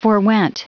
Prononciation du mot forewent en anglais (fichier audio)
Prononciation du mot : forewent